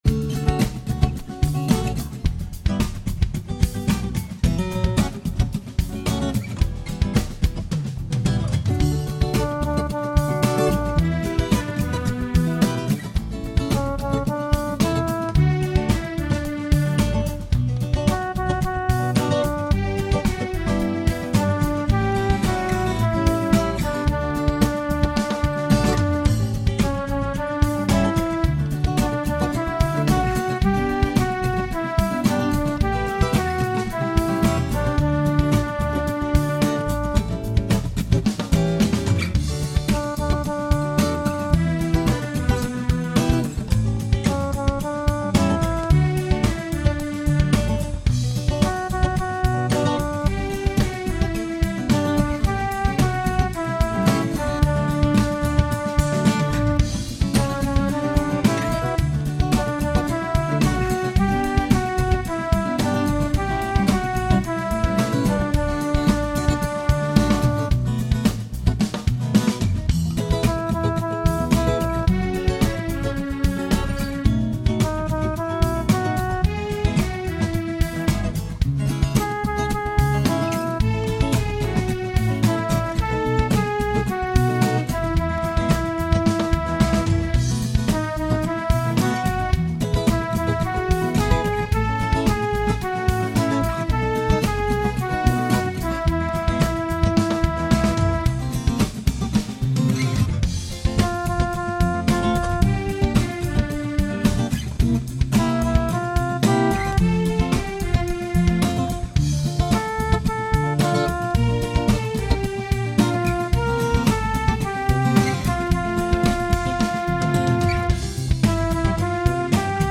My backing has the call and response and key changes.